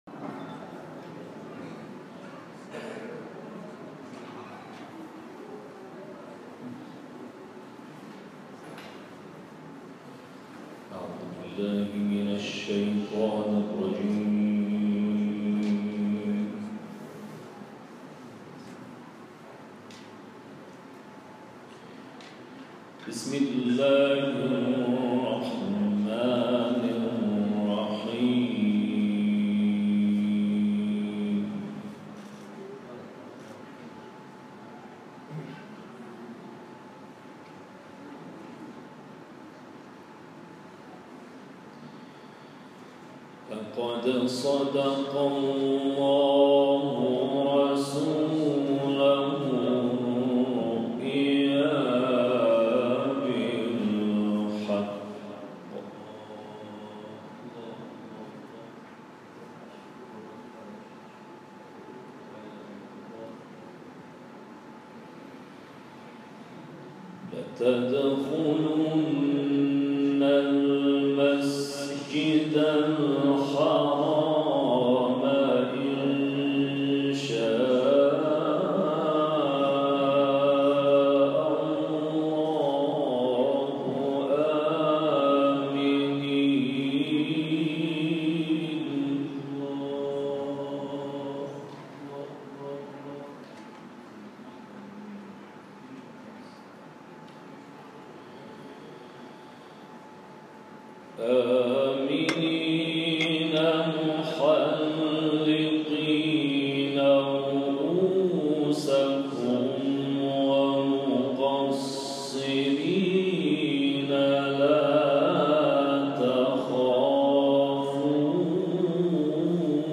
تلاوت آیاتی از سوره فتح